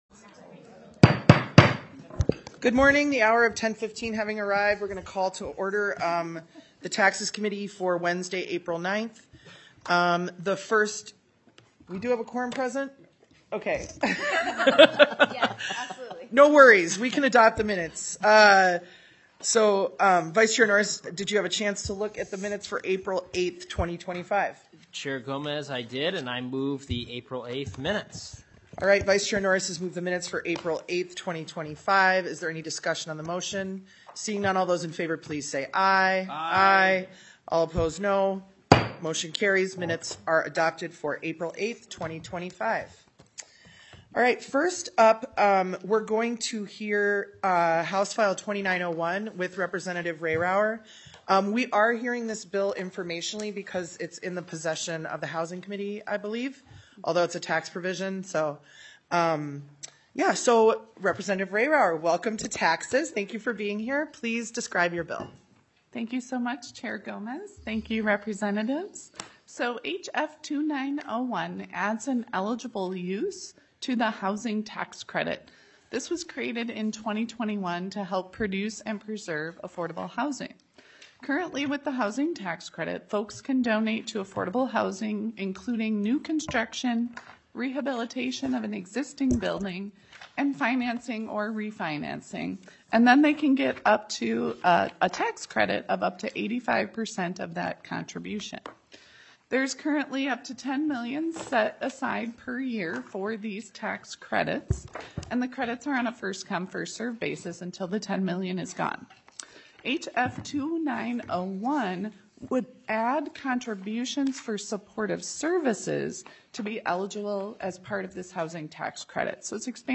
Taxes TWENTY-FIFTH MEETING - Minnesota House of Representatives